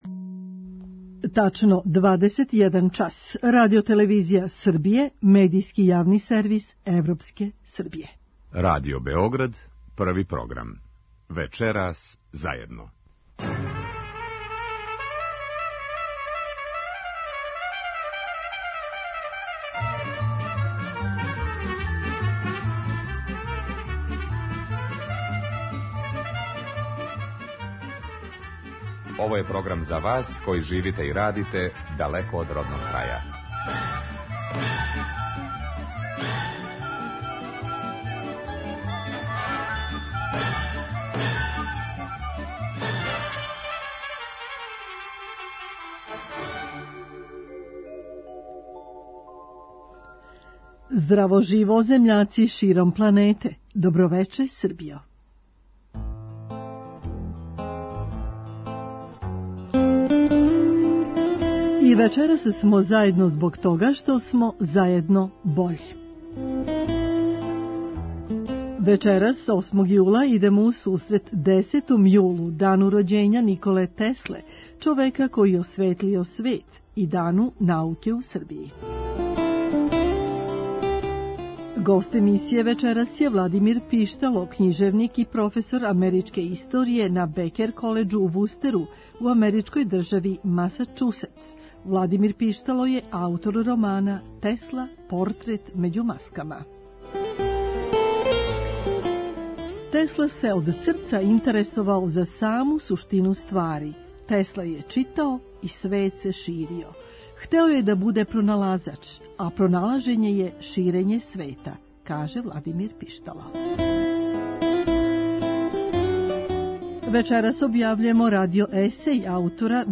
Гост емисије је Владимир Пиштало, књижевник и професор америчке историје на Бекер колеџу у Вустеру, у америчкој држави Масачусетс.